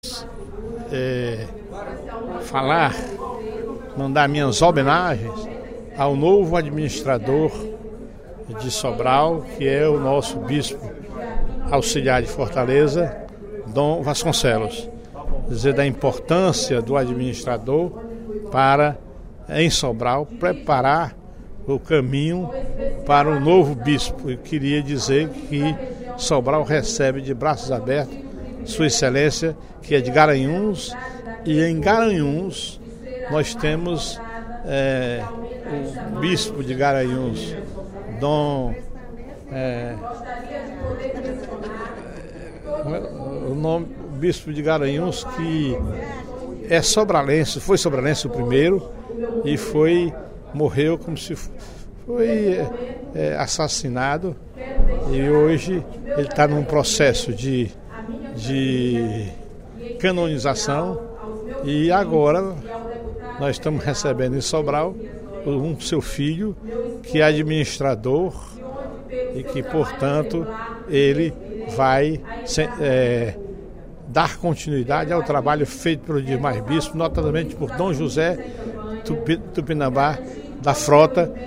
Durante o primeiro expediente da sessão plenária desta quarta-feira (11/02), o deputado Professor Teodoro (PSD) deu boas vindas ao bispo Dom Vasconcelos, que assumiu na última semana a administração da Diocese de Sobral.